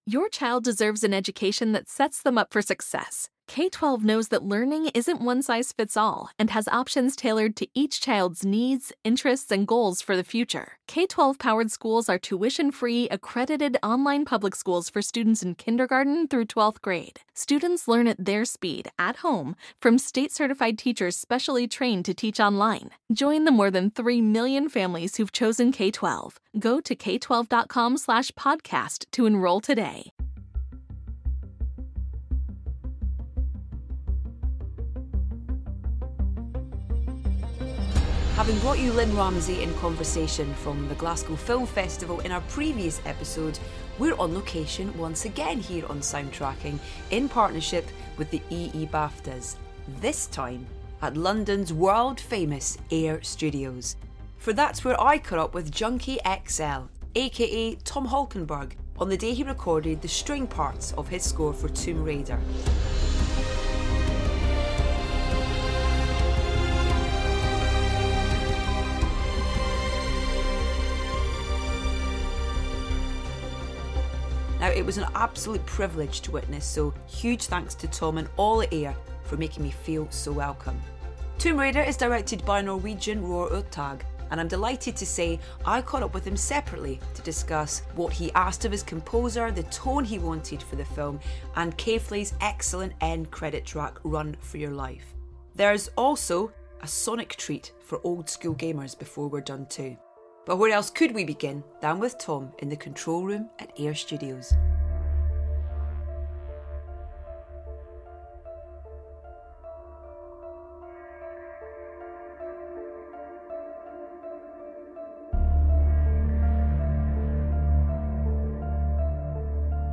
Having brought you Lynne Ramsay in conversation from the Glasgow Film Festival in our previous episode, we're on location once again here on Soundtracking in partnership with the EE BAFTAs, this time at London's world-famous AIR studios.
For that's where Edith caught up with Junkie XL - aka Tom Holkenborg - on the day he recorded the string parts of his score for Tomb Raider.
But where else could we begin than with Tom in the control room at AIR …